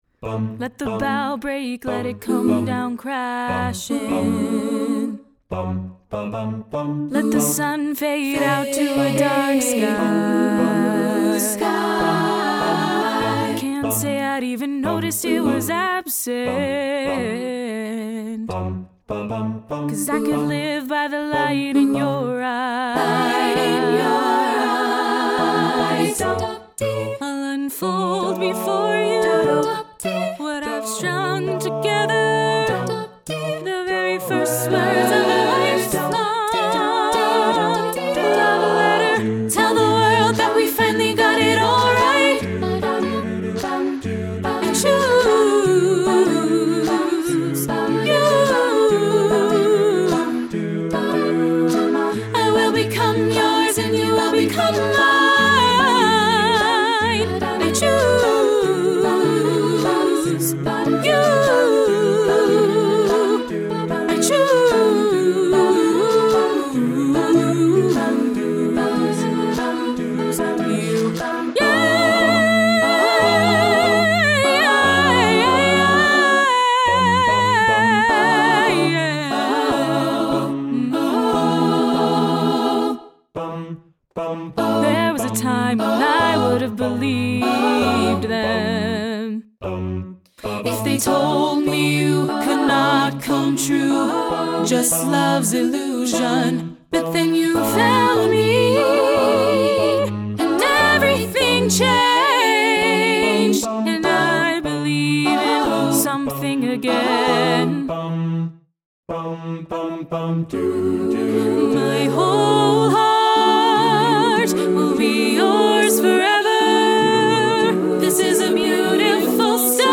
We operate on the individual-take model.